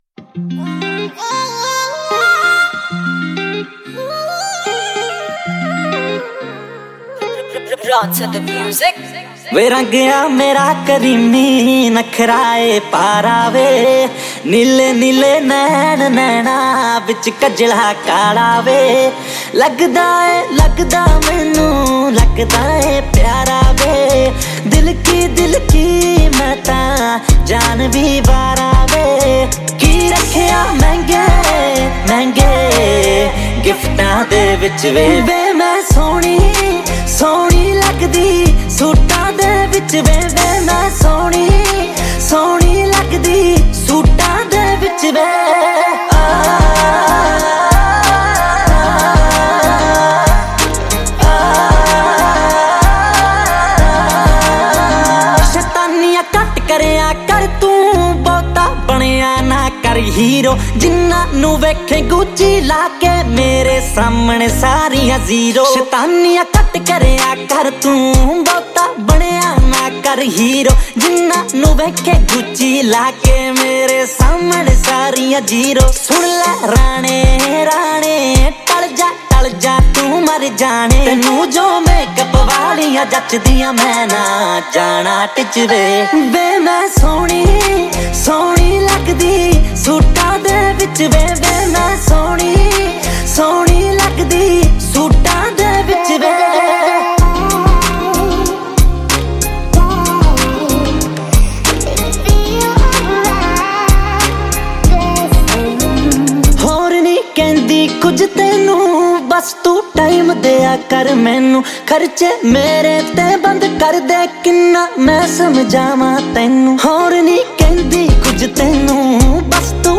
2020 Pop Mp3 Songs
Punjabi Bhangra MP3 Songs